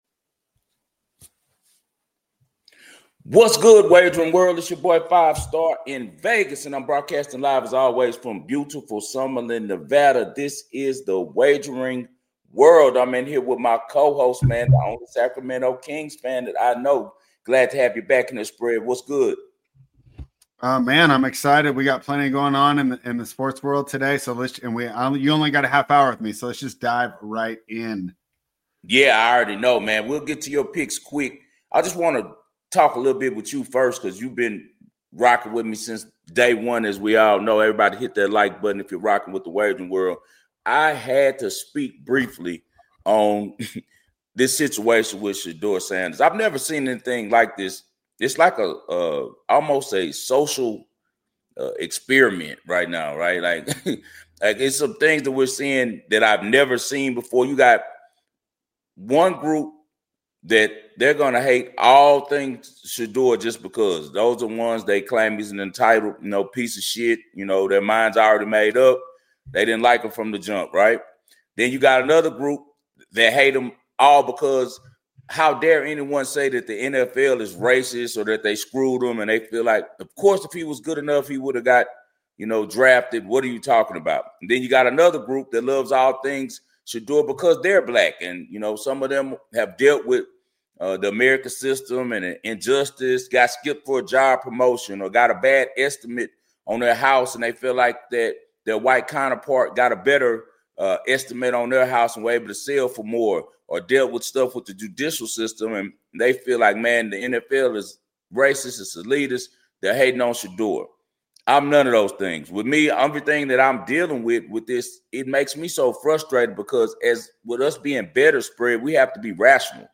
Next the duo review last night’s SNF. In the Present segment the two betting experts deep dive into a huge NBA NFL and CFB for the early week.